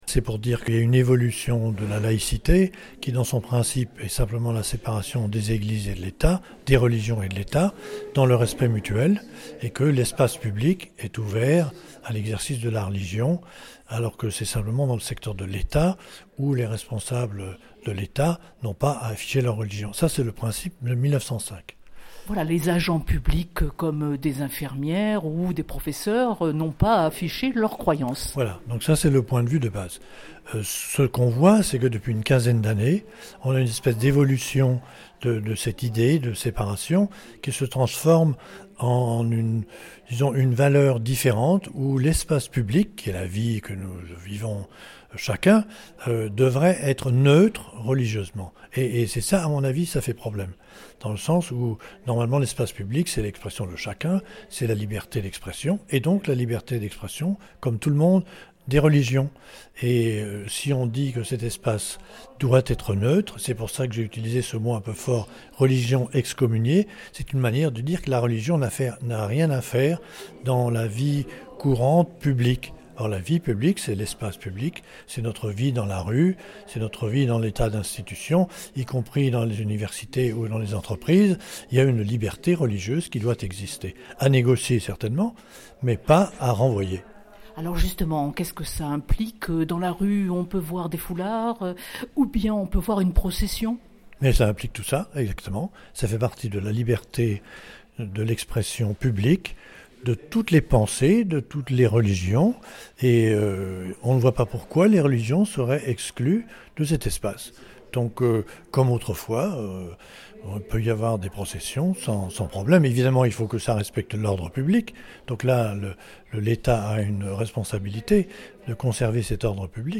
Marseille: riche débat à l’Institut Catholique de la Méditerranée sur ce que devient la laïcité